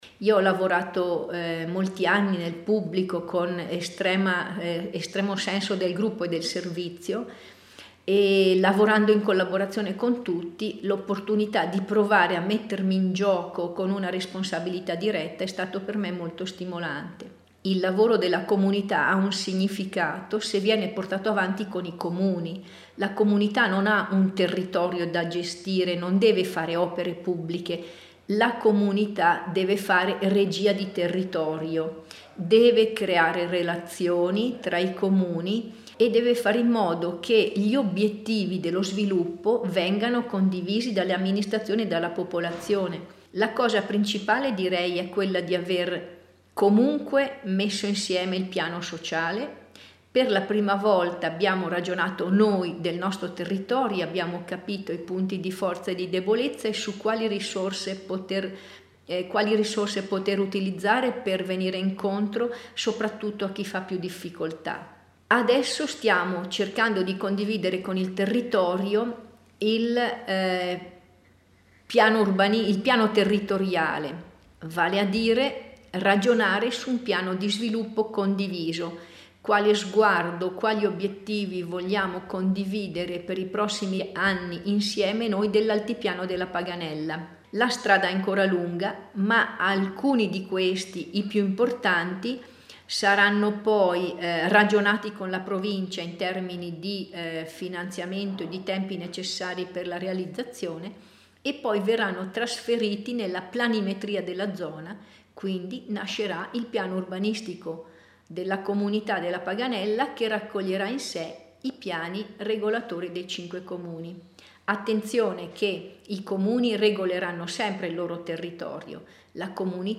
Presentazione audio della presidente Donata Sartori